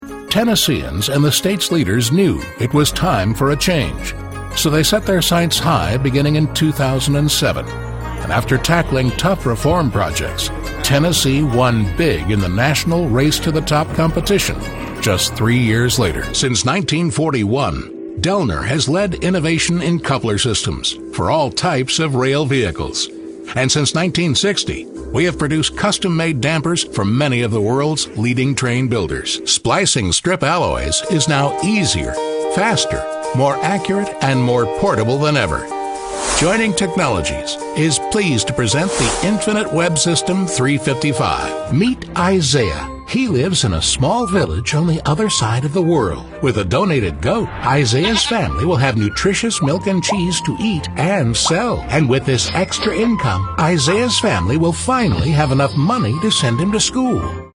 He has a rich, warm voice, a natural style, and can also be very funny.
middle west
Sprechprobe: Industrie (Muttersprache):